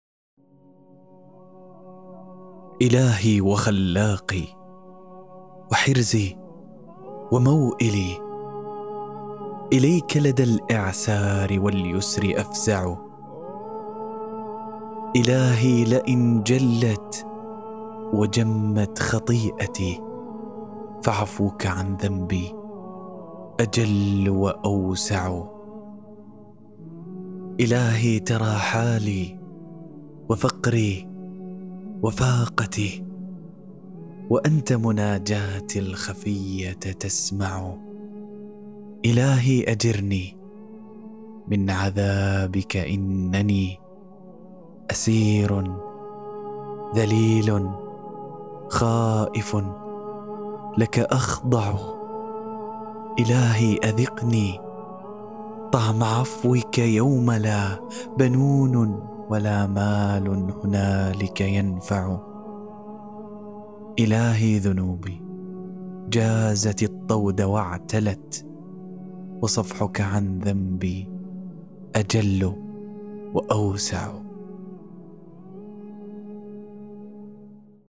قصيدة شعرية فصيحة